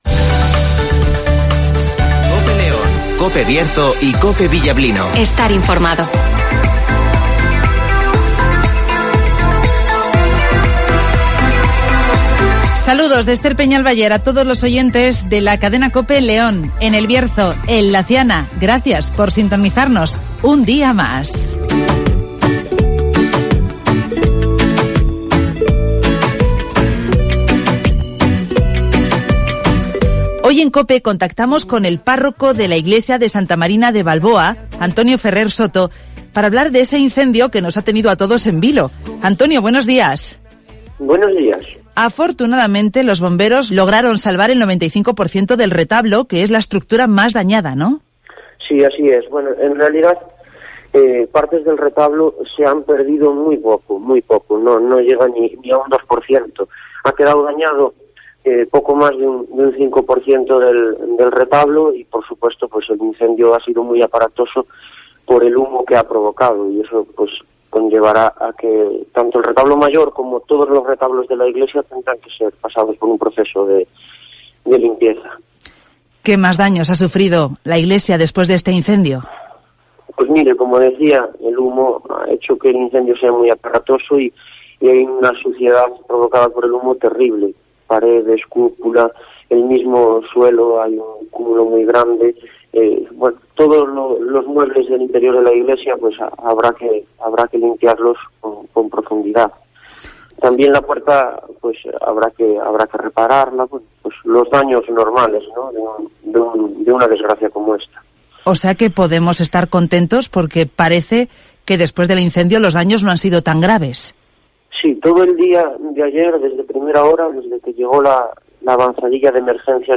El 95 % del retablo mayor de la iglesia de Santa Marina de Balboa se salva del incendio (Entrevista